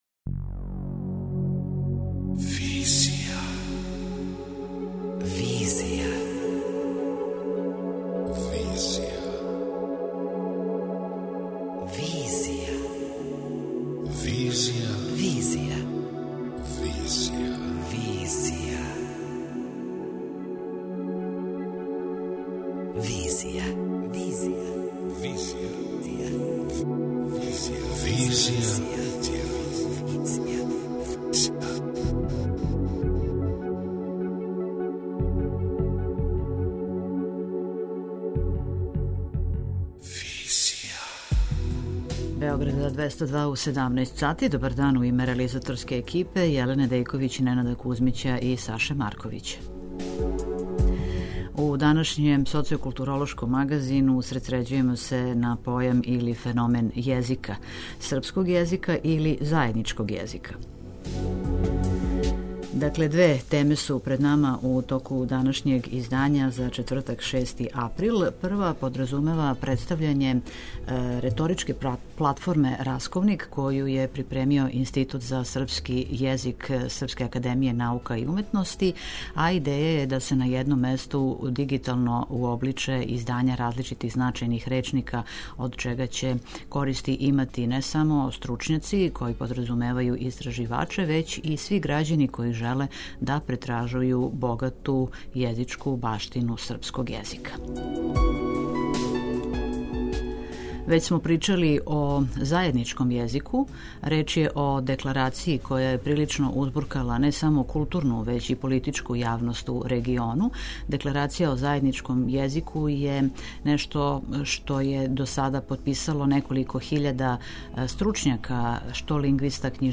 Писац Владимир Арсенијевић , из Удружења Крокодил које је једно од иницијатора пројекта, за наш програм говори о тренутним актуелностима везаним за ову ‘'Декларацију''.